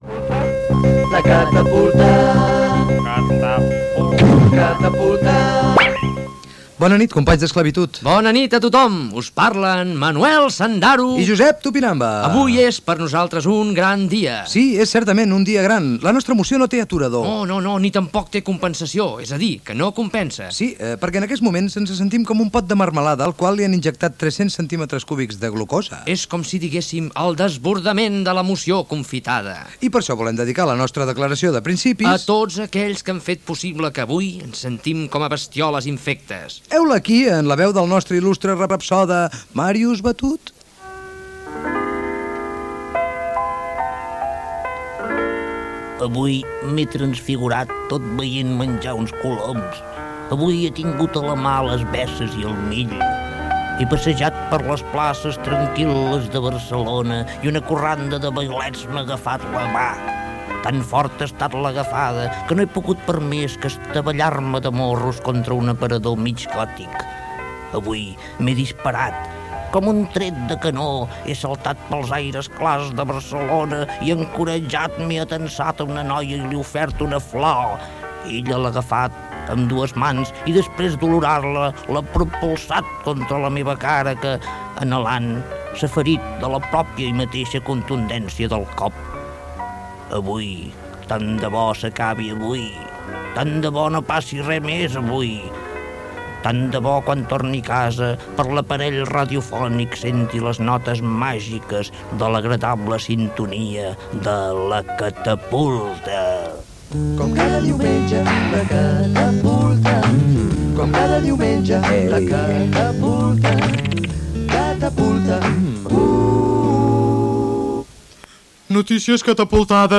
Careta del programa, presentació
Gènere radiofònic Entreteniment